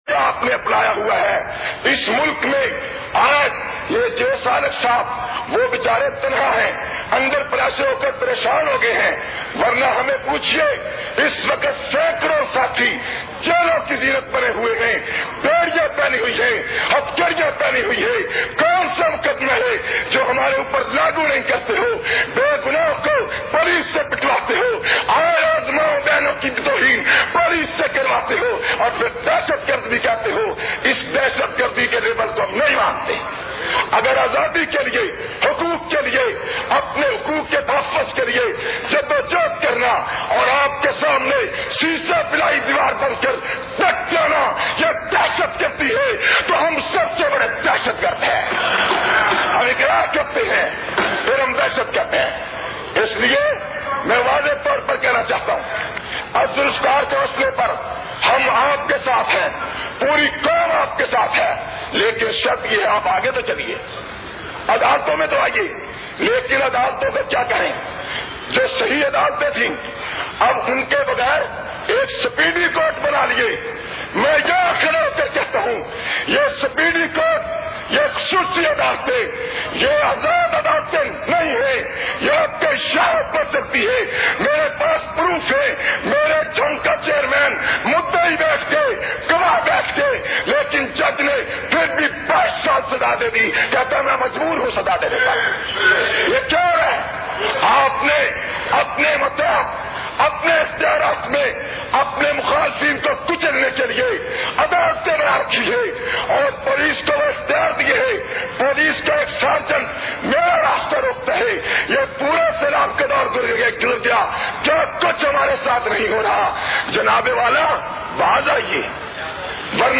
12- Assembly khitab.mp3